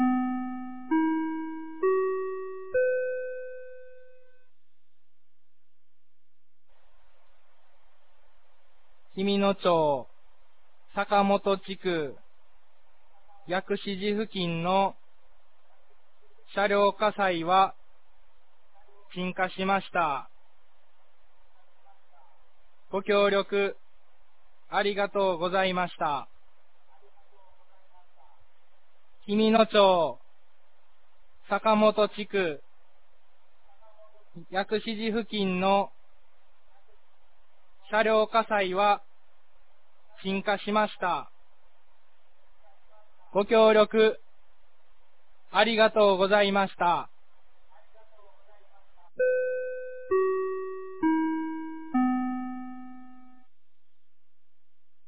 2023年05月05日 14時36分に、紀美野町より全地区へ放送がありました。